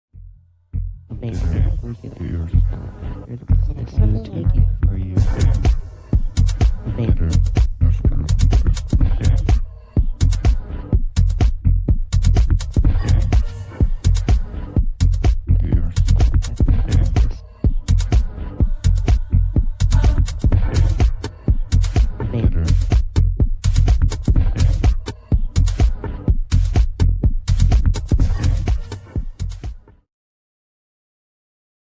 minimal